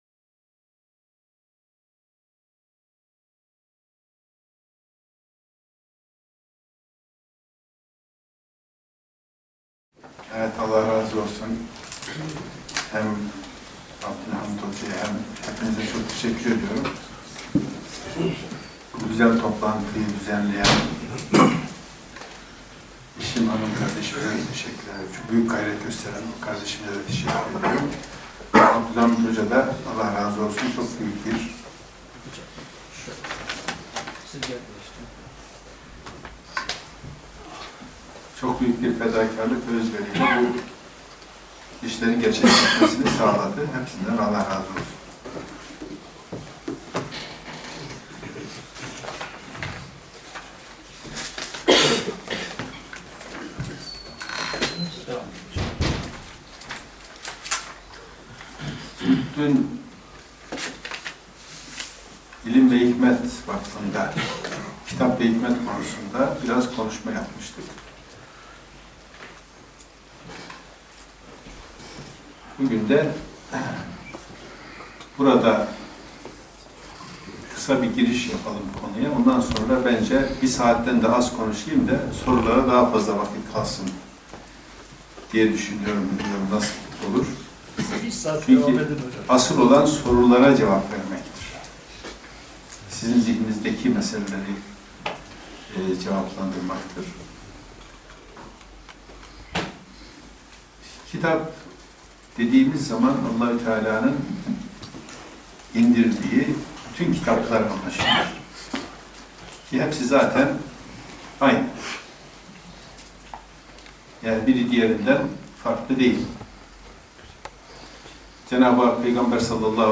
Konferanslar